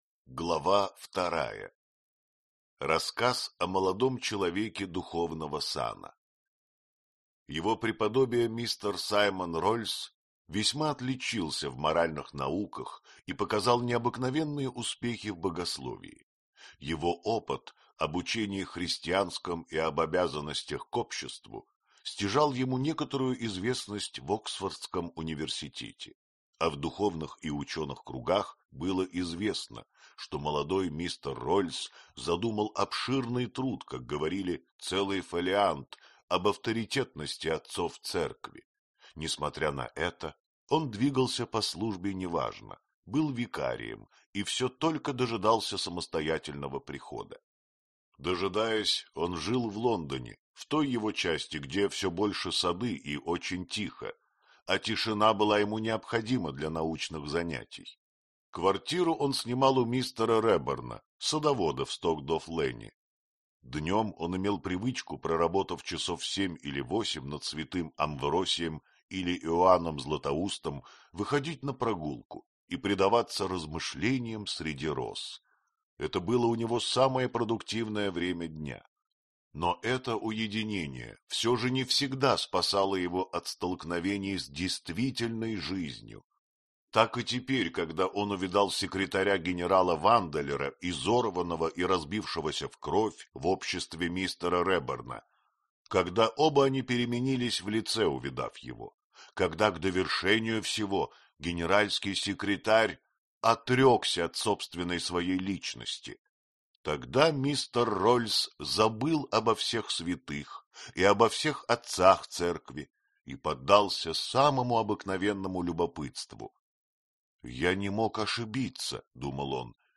Аудиокнига Клуб самоубийц | Библиотека аудиокниг